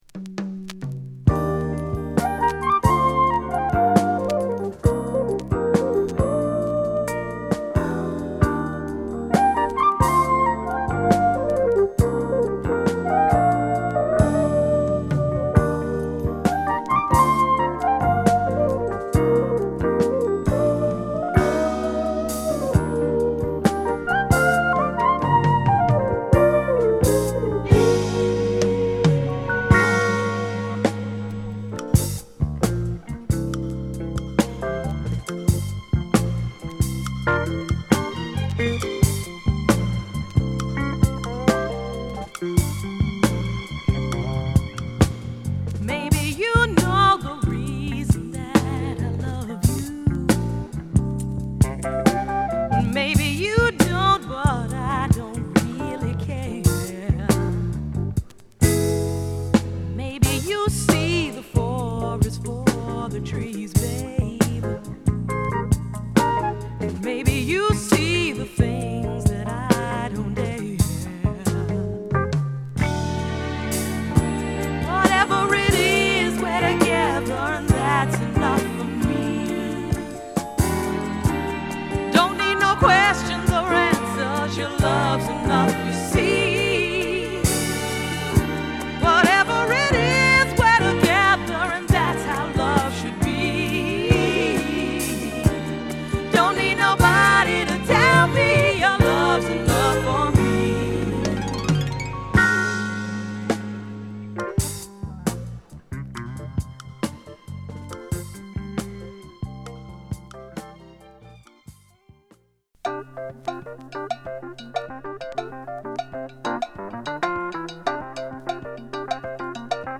心地良いメロウチューンを多数収録！